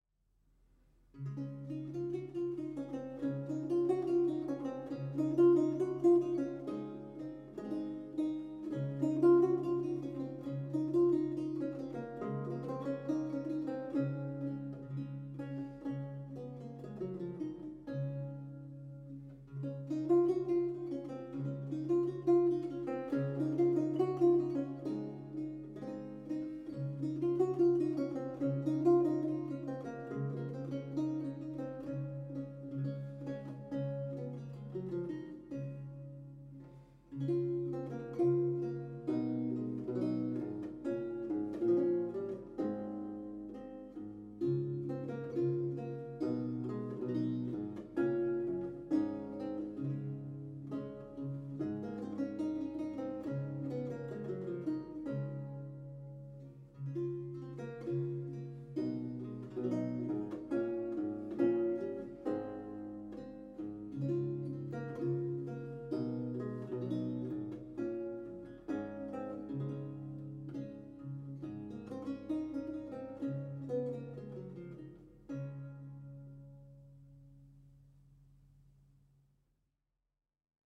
a 16th century lute music piece originally notated in lute tablature
Audio recording of a lute piece from the E-LAUTE project